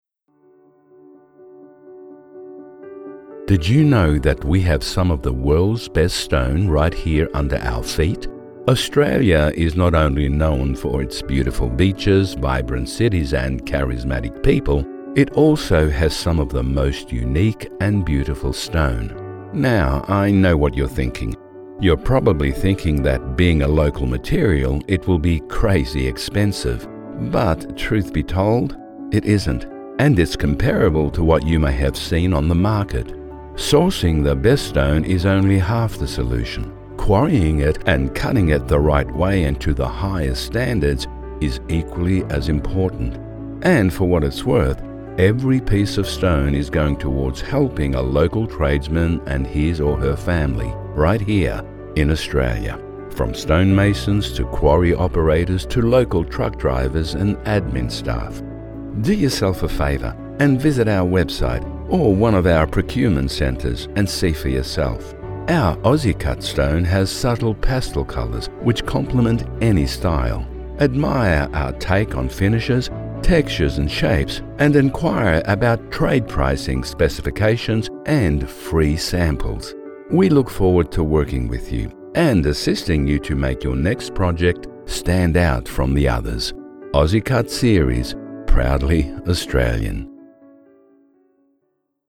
Male
English (Australian)
Older Sound (50+)
Tv Commercial Soft Sell
Words that describe my voice are Warm, Corporate, Authoritative.